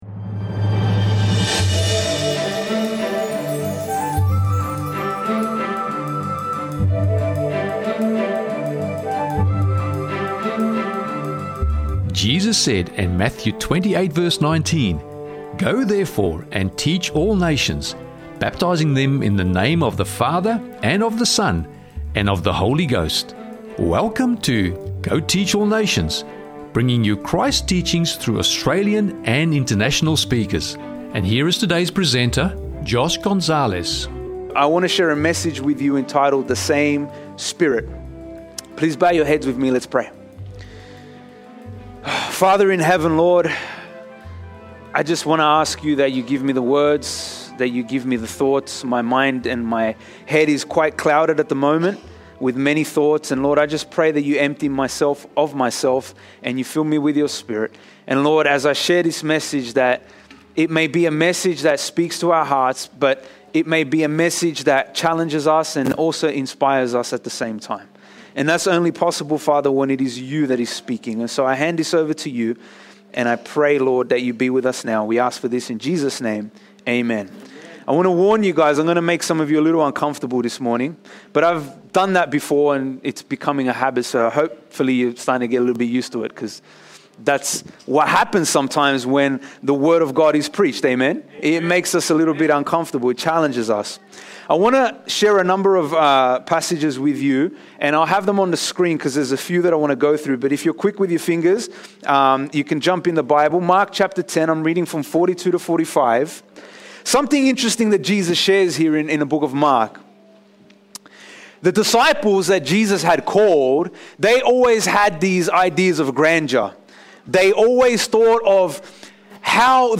The Same Spirit That Empowers Us – Sermon Audio 2612